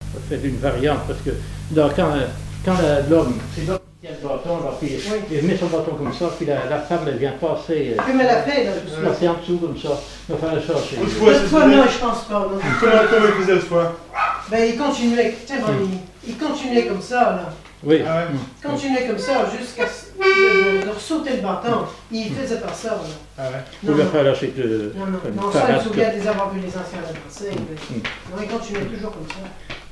accordéon diatonique et témoignages sur les musiciens
Catégorie Témoignage